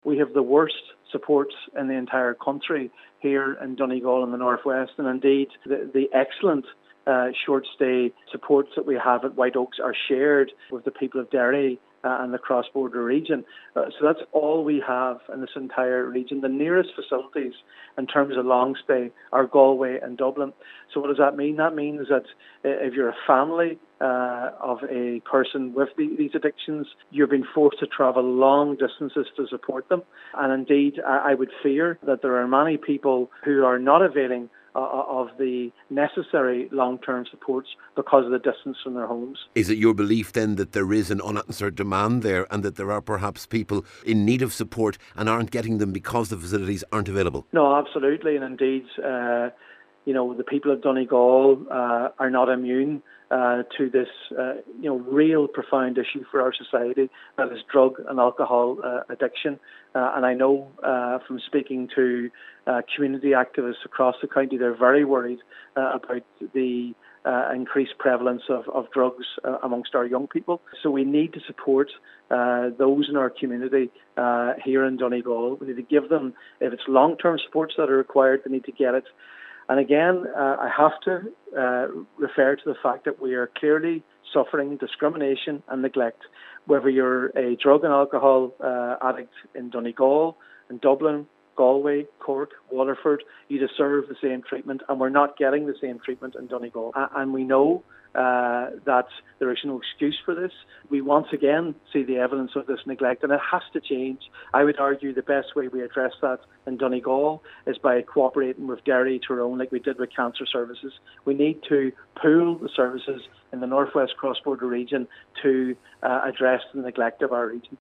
Senator Mac Lochlainn says this shortfall should be addressed at a regional level, that also includes Derry and Tyrone: